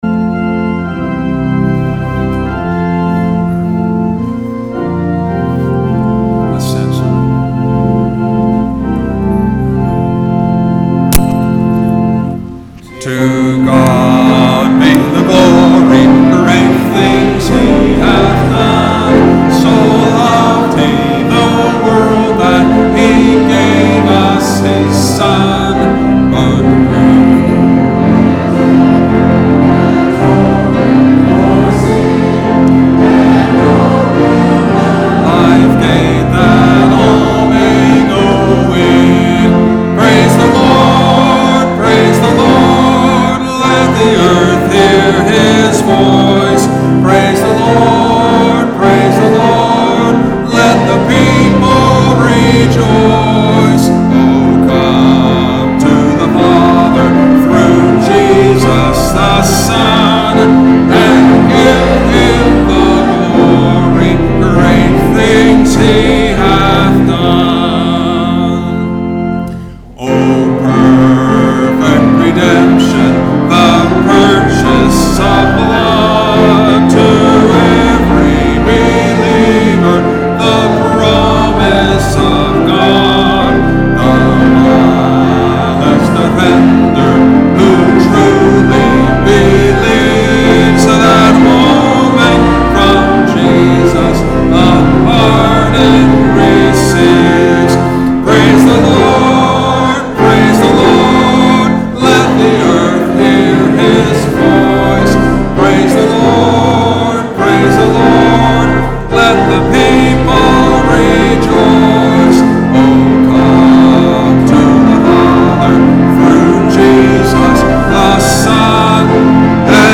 Sermons -